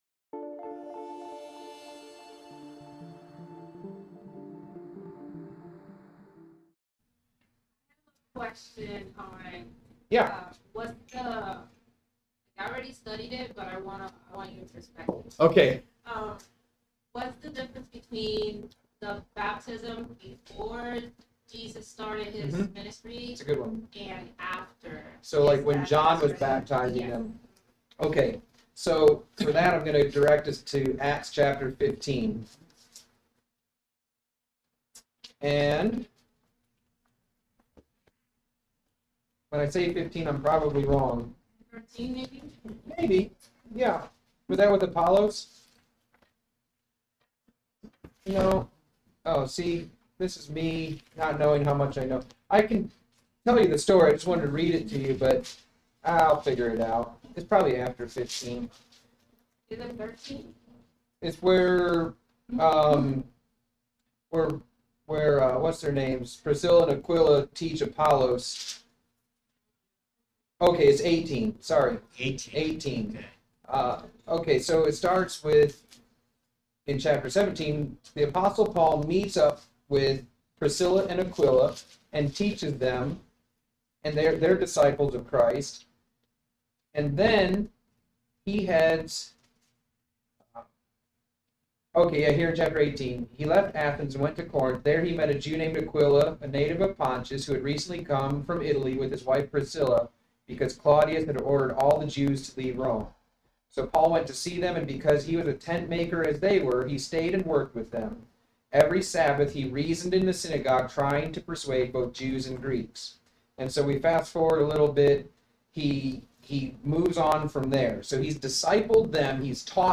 From Sunday School on Sept 29, 2024